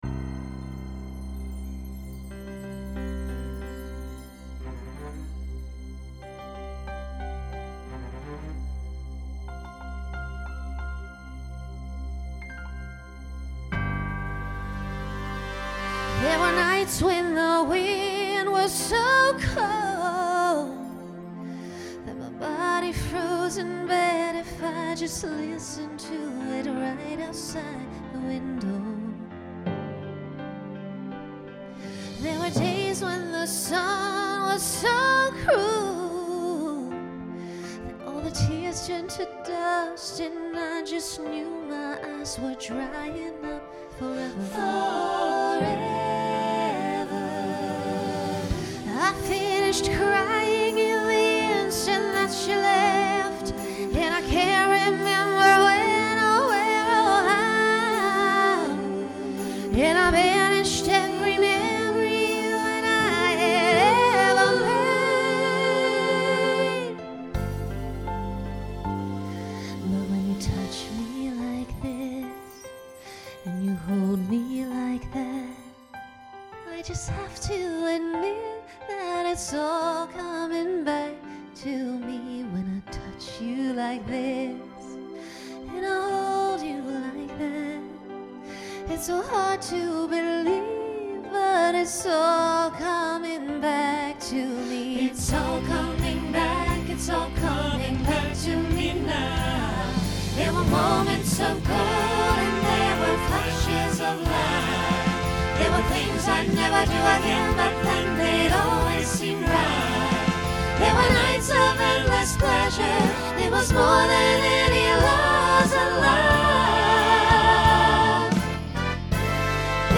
Genre Pop/Dance
Ballad , Solo Feature Voicing SATB